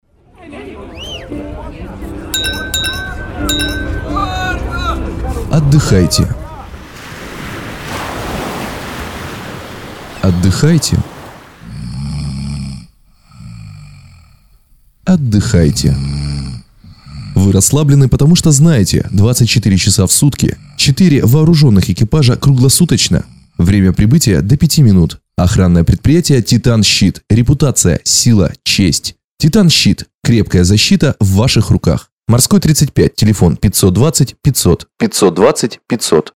Радиоролик группы охранных предприятий (сценарий) Категория: Копирайтинг